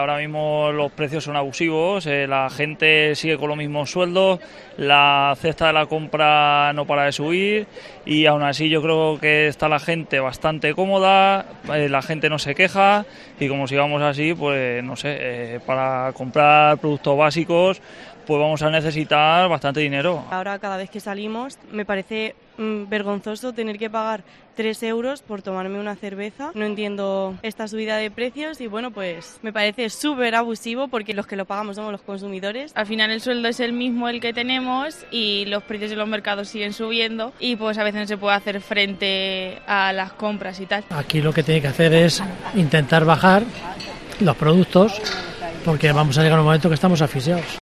En COPE hemos salido a la calle para conocer la opinión de los ciudadrealeños acerca de los datos proporcionados por el INE y como afectan a su economía personal
Vecinos de Ciudad Real opinando sobre la subida del IPC